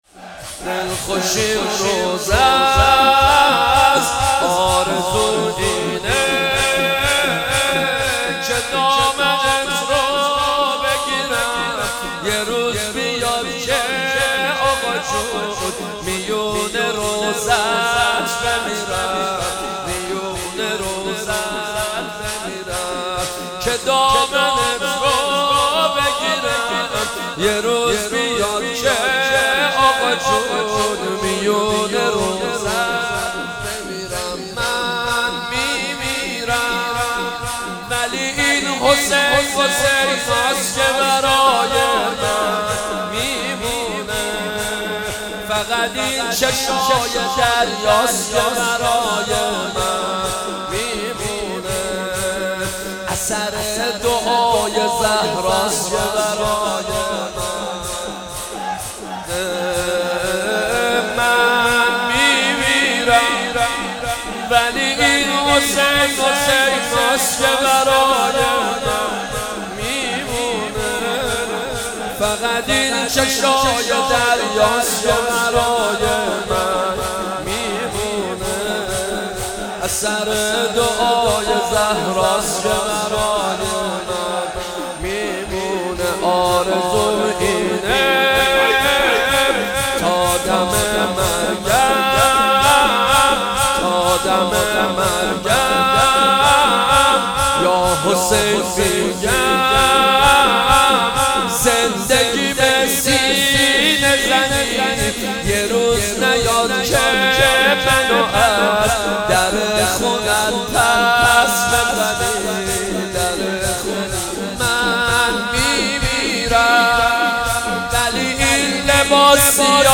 صابر خراسانی ولادت حضرت عباس (ع) هیئت مکتب العباس(ع) خمینی شهر پلان3